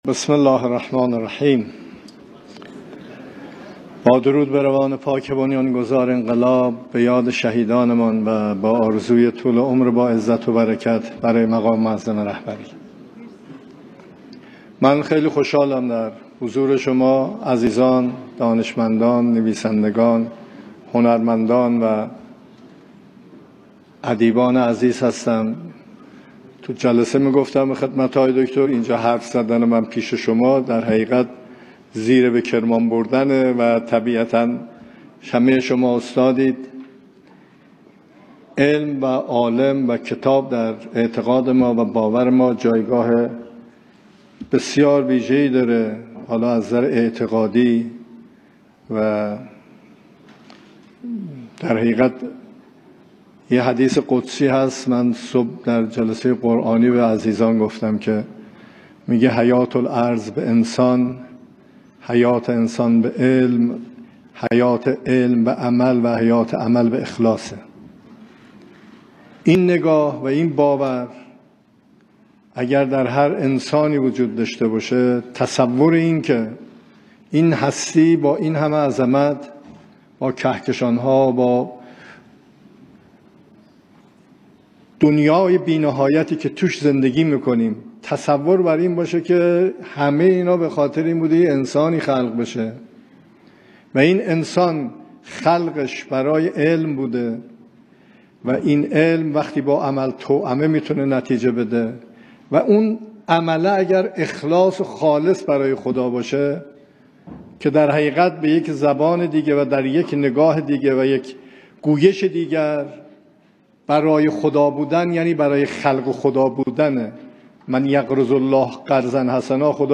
سخنان رییس جمهور در اختتامیه جایزه کتاب سال